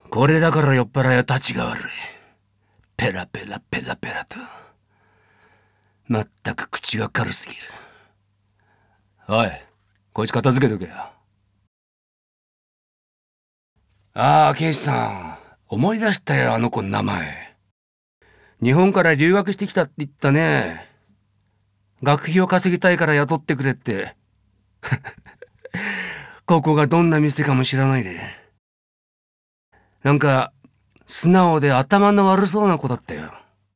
ボイスサンプル、その他